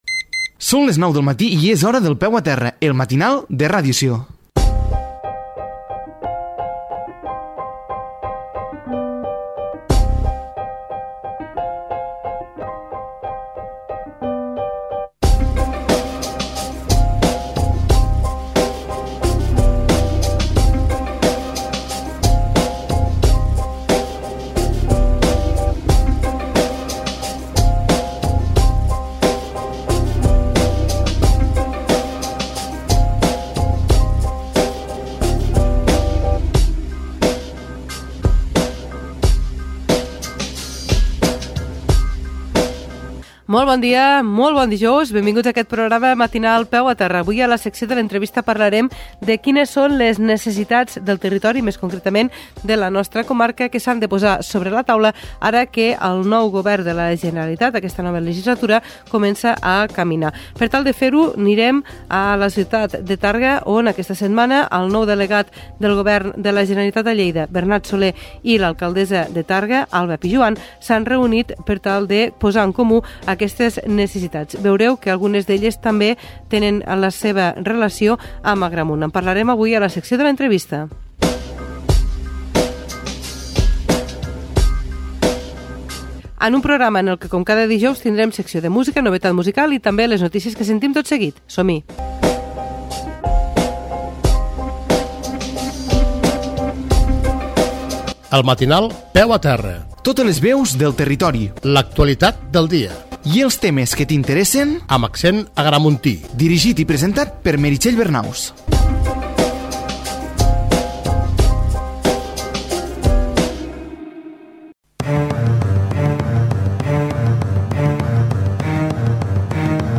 Careta, sumari de continguts del programa, indicatiu, titulars de les notícies.
Informatiu